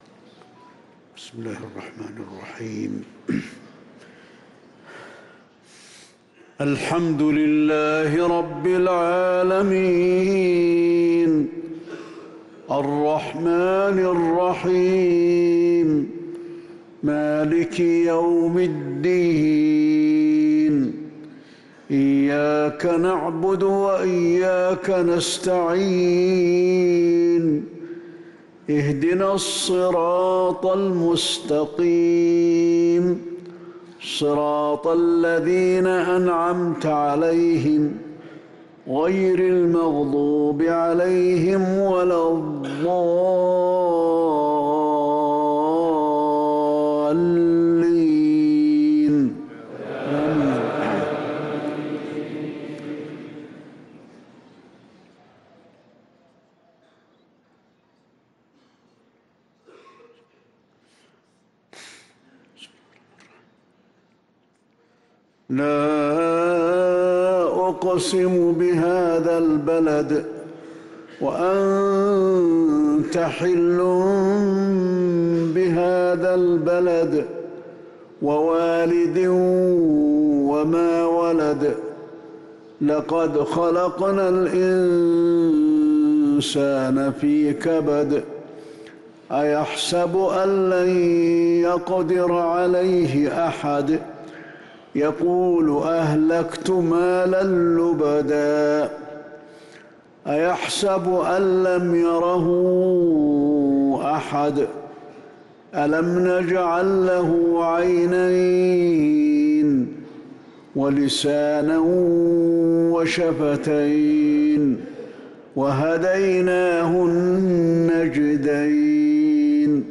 صلاة المغرب للقارئ علي الحذيفي 29 جمادي الآخر 1445 هـ
تِلَاوَات الْحَرَمَيْن .